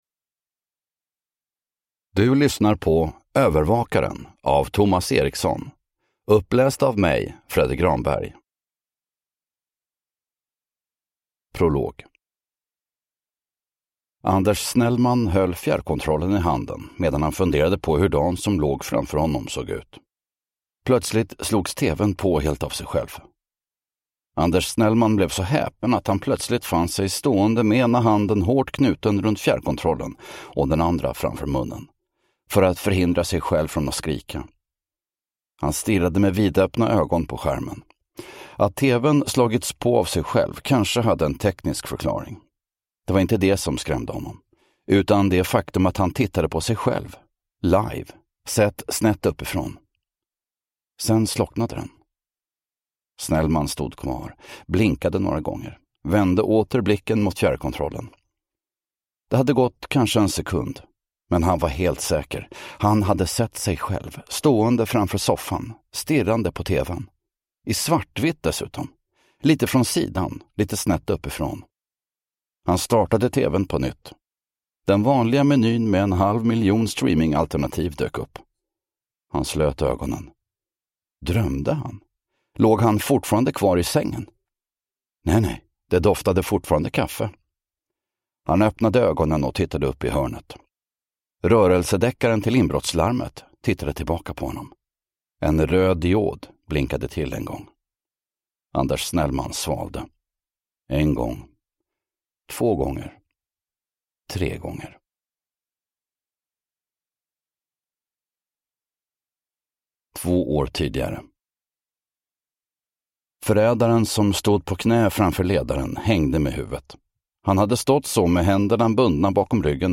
Övervakaren (ljudbok) av Thomas Erikson